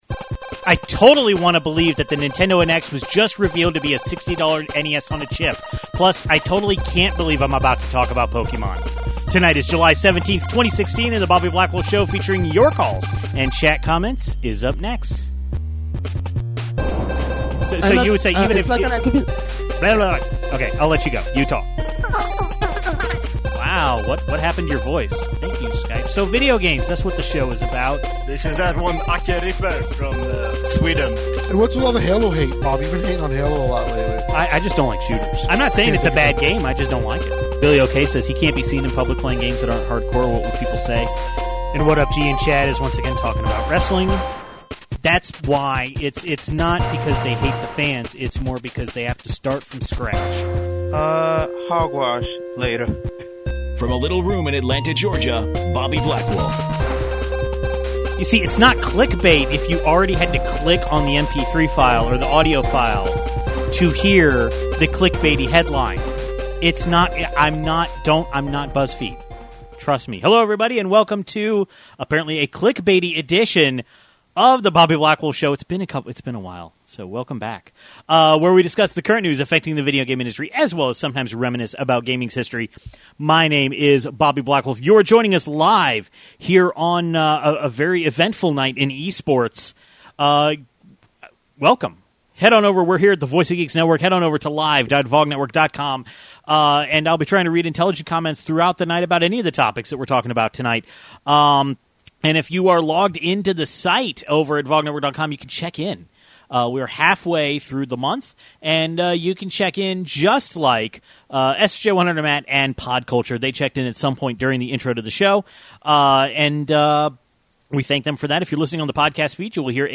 The NES Classic Edition will be out in November with 30 games. Pokemon Go is out and has been a bigger cultural sensation than anybody realizes - and even though the mainstream media has been negative, it has been an overall net positive for civilization. Then we take calls about the Gear VR, FandomFest (where I'll be in two weeks!), and Pokemon Go.